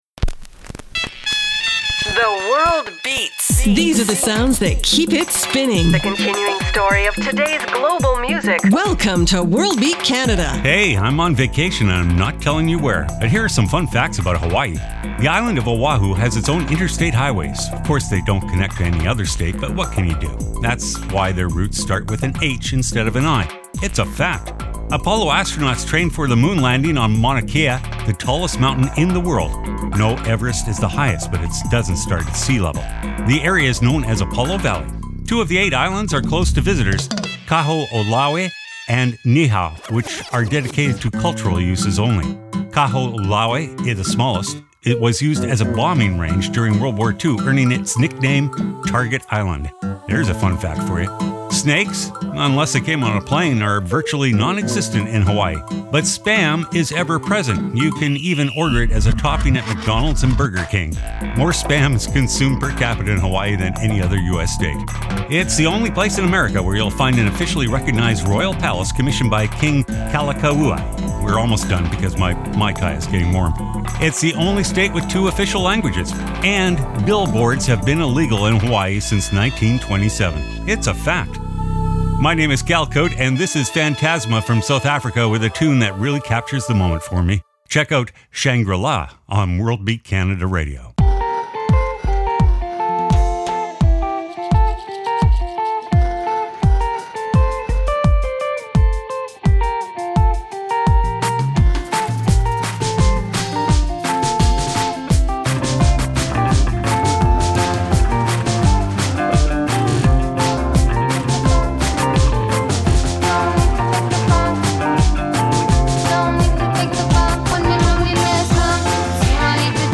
exciting contemporary global music alternative to jukebox radio
File Information Listen (h:mm:ss) 0:59:57 worldbeatcanada radio march 20 2015 Download (9) WBC_Radio_March_20_2015.mp3 71,957k 0kbps Stereo Comments: Tropical Vacation rhythms for your spring break soundtrack.